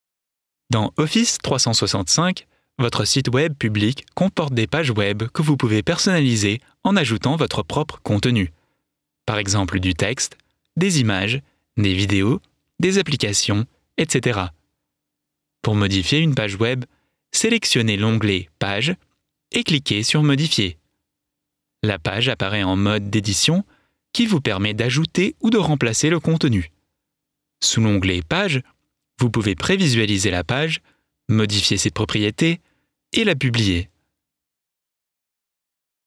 Junge, Natürlich, Warm, Sanft, Corporate
E-learning